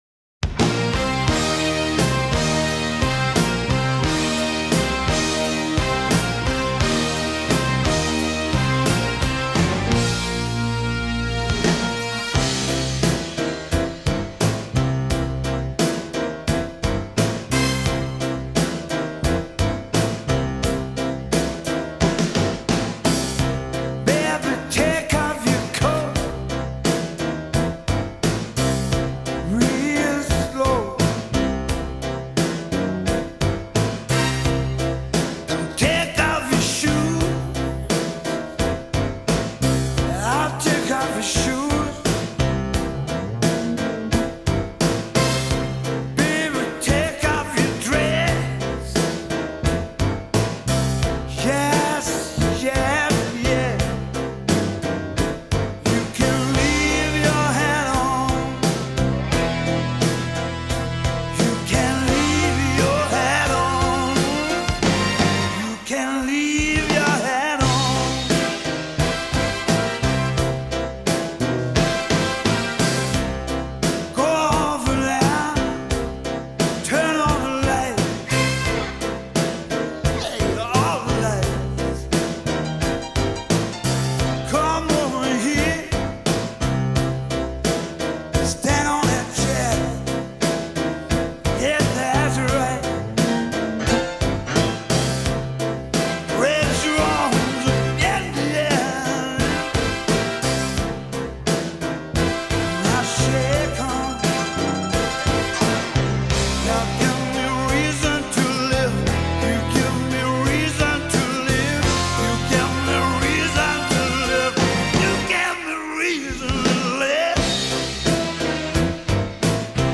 эротической и эмоциональной композиции